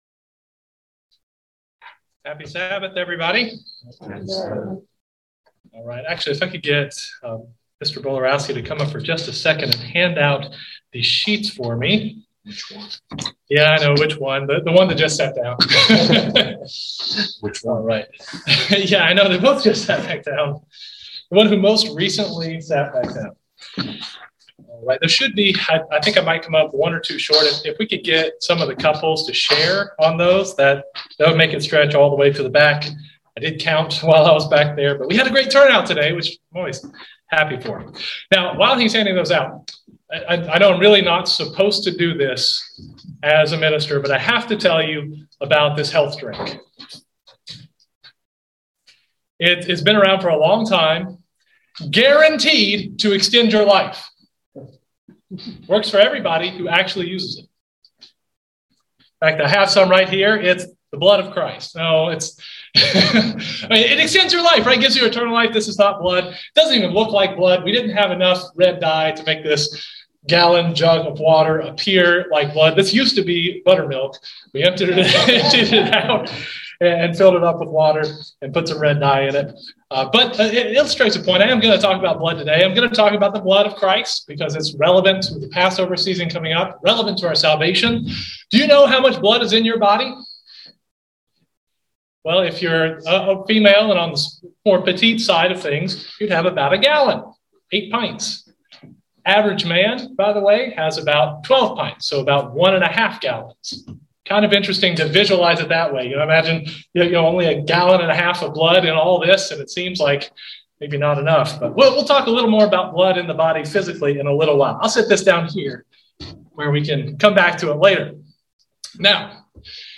Given in Central Georgia Columbus, GA